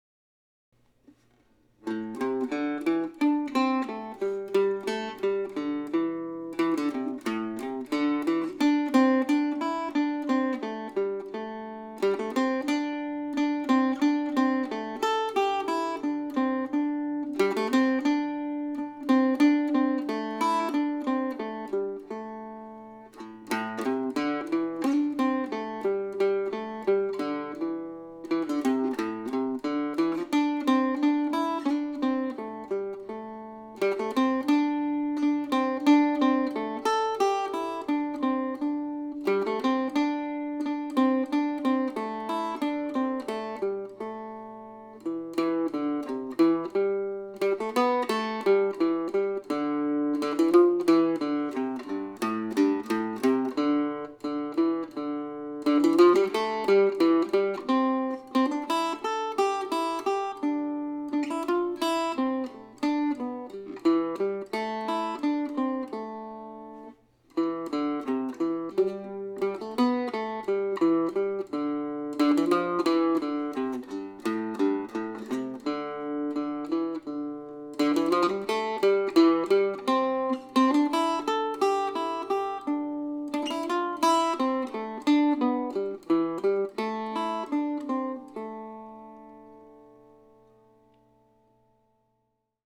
Summer Suite, August, 2020 (for Octave Mandolin or Mandocello)
I added short introductions to a couple of the pieces, I changed keys a couple of times and I added drones here and there.